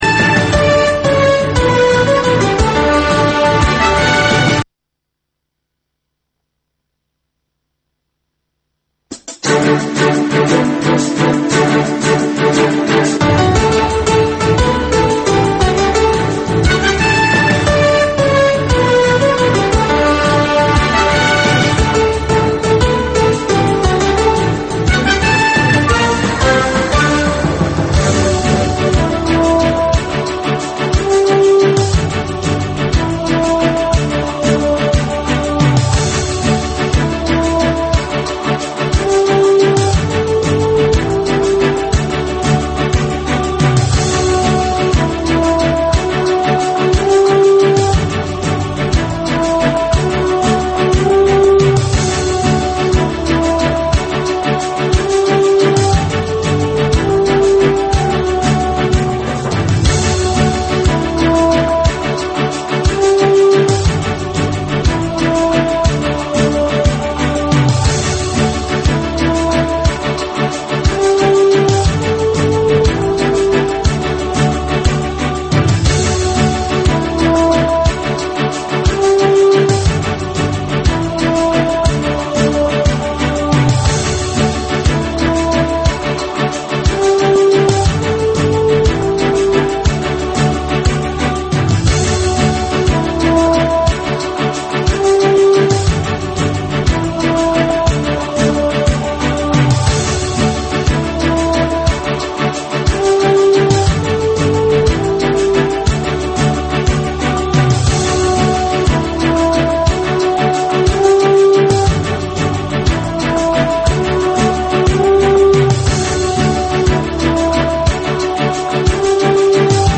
Бул жаштарга арналган кечки үналгы берүү жергиликтүү жана эл аралык кабарлардын чакан топтому, ар кыл репортаж, сереп, маек, маданий, спорттук, социалдык баян, тегерек үстөл четиндеги баарлашуу жана башка кыргызстандык жаштардын көйгөйү чагылдырылган берүүлөрдөн турат. "Азаттык үналгысынын" бул жаштар берүүсү Бишкек убакыты боюнча саат 20:00дан 20:30га чейин обого түз чыгат.